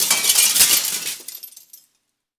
glass_smashable_debris_fall_01.wav